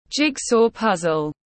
Tranh xếp hình tiếng anh gọi là jigsaw puzzle, phiên âm tiếng anh đọc là /ˈdʒɪɡ.sɔː ˌpʌz.əl/